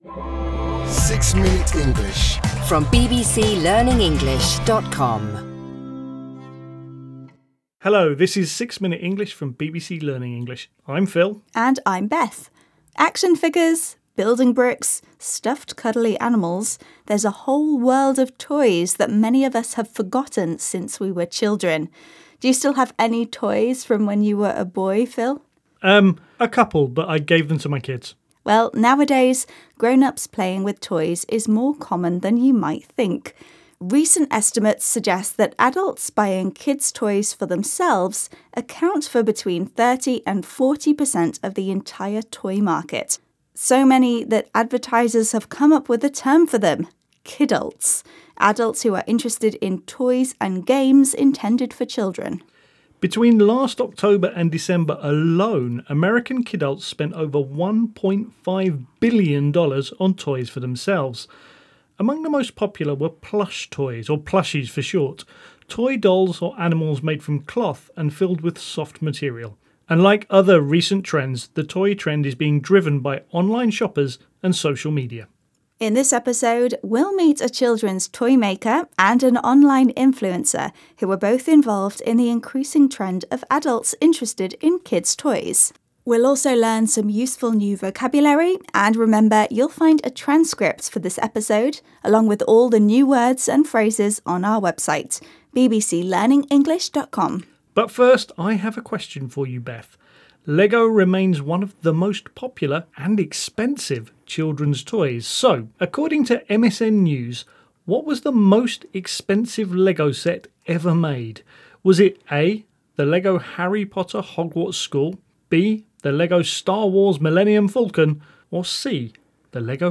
گویندگان این پادکست با لهجه انگلیسی بریتانیایی (British English) صحبت می‌کنند که منبعی ایده‌آل برای افرادی است که قصد شرکت در آزمون آیلتس دارند.
هر قسمت این پادکست شامل گفت‌وگویی کوتاه و جذاب درباره موضوعات متنوعی است که به زبان ساده و قابل‌فهم ارائه می‌شود تا به شنوندگان در تقویت مهارت‌های شنیداری، مکالمه و یادگیری واژگان جدید کمک کند.